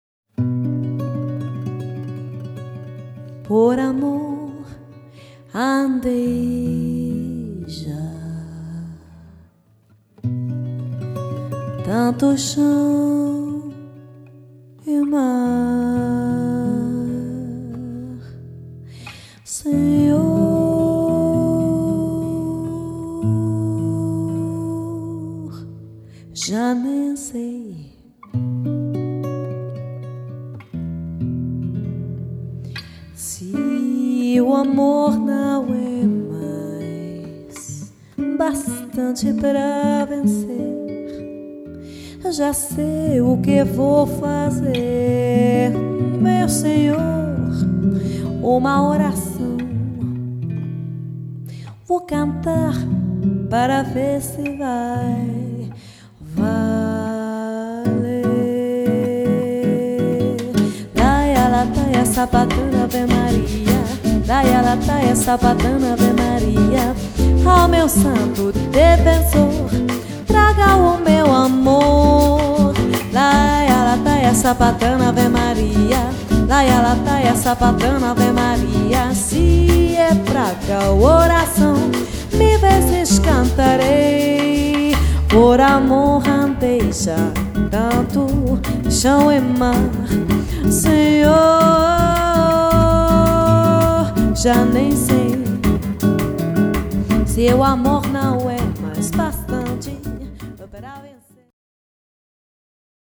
voce
chitarra
contrabbasso
rullante e charleston